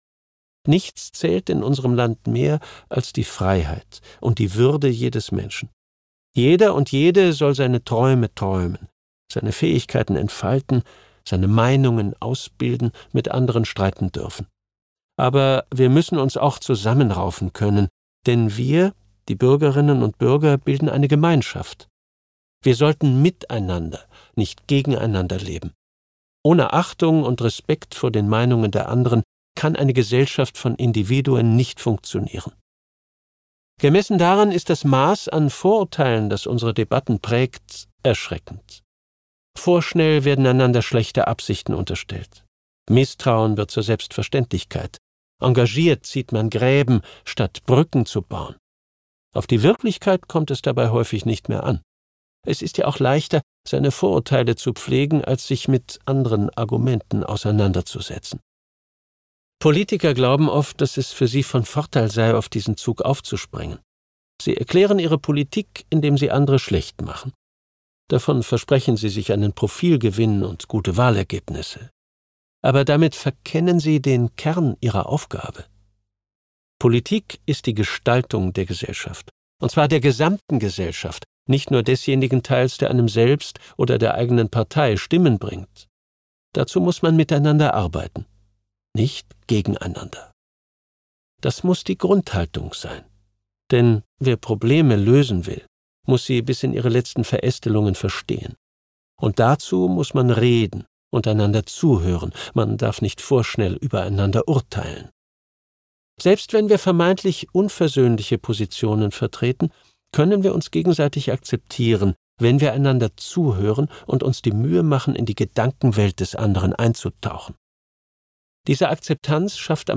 Dieses Sachhörbuch ist ein zeitgemäßer Appell zur politischen Verantwortung und ein konkreter Vorschlag, wie die liberale Demokratie auch in Krisenzeiten handlungsfähig bleibt.
Gekürzt Autorisierte, d.h. von Autor:innen und / oder Verlagen freigegebene, bearbeitete Fassung.